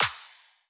osu-logo-heartbeat.wav